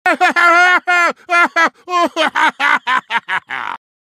Risada do lutador Braum de League Of Legends (LoL).
risada-braum-lol.mp3